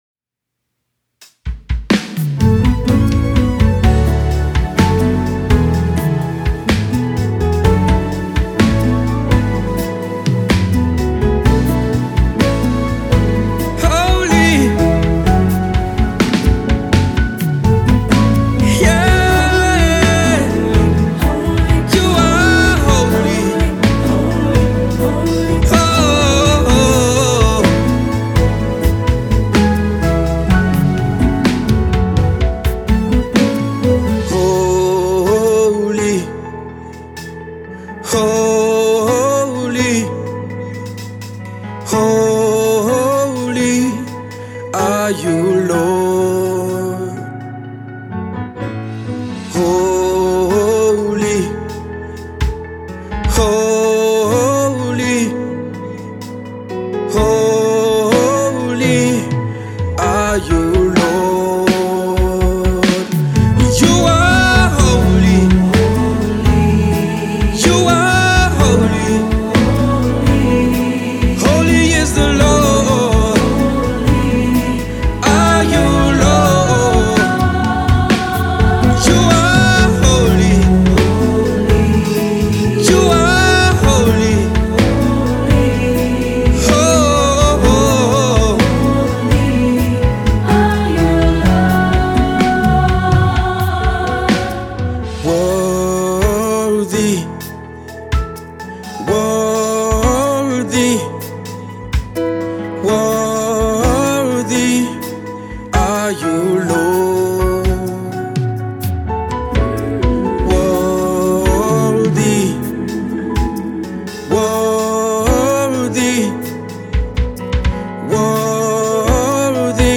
drops a brand new gospel music and an official video for
worship song